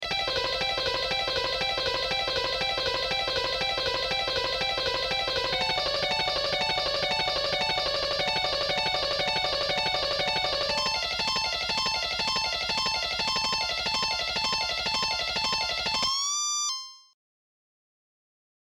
Shredding Exercises > Fast Shredding Exercise
Fast+Shredding+Exercise.mp3